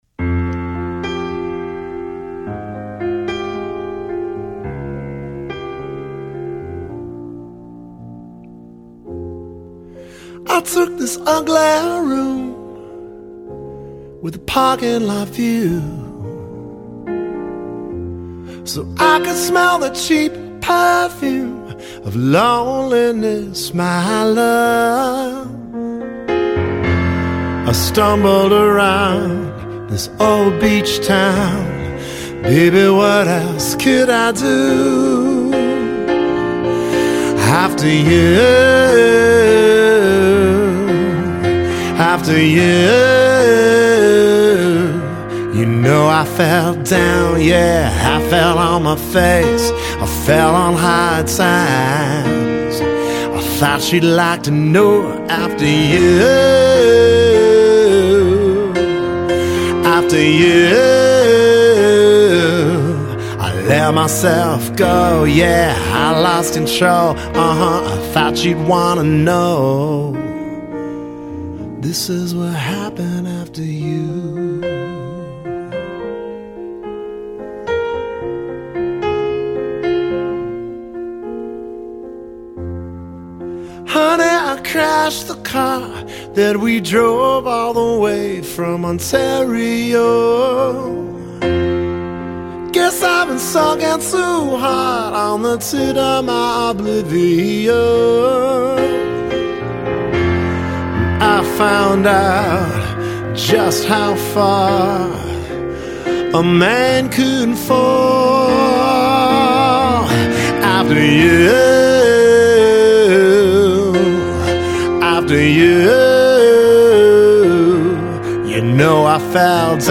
Ballad Ml vx, piano